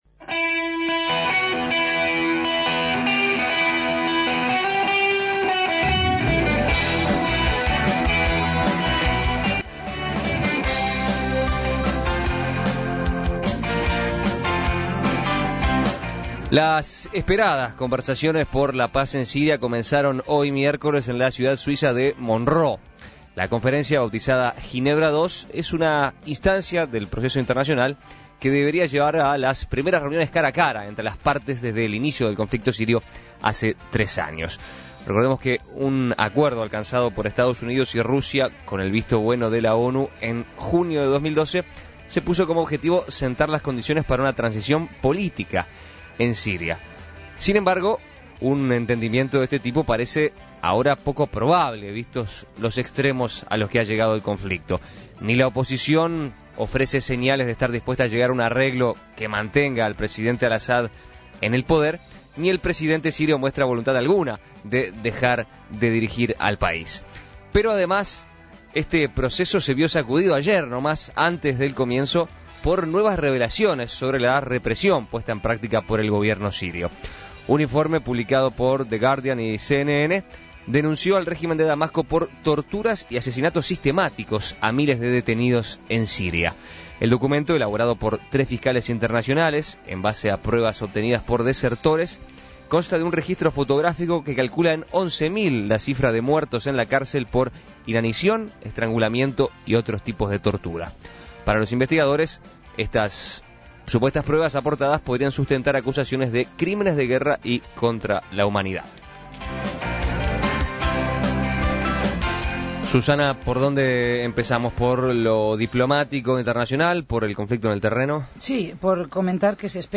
La Tertulia